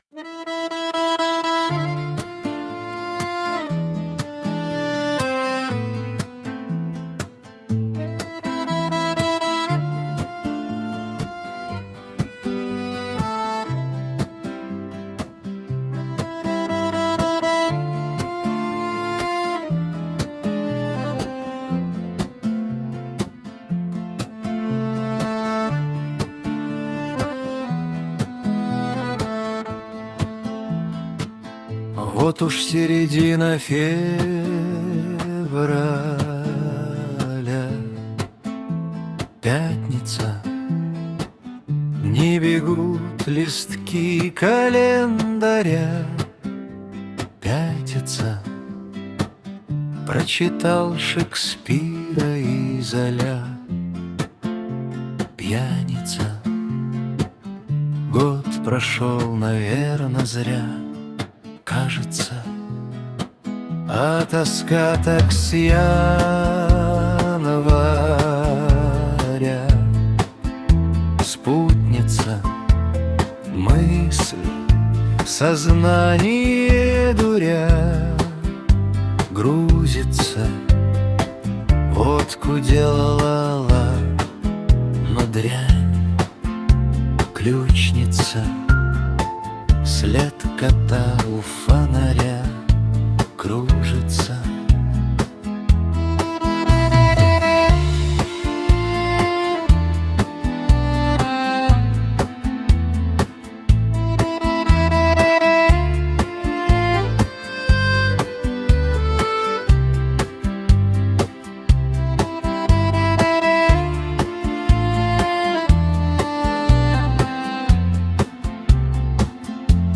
• Аранжировка: Ai
• Жанр: Шансон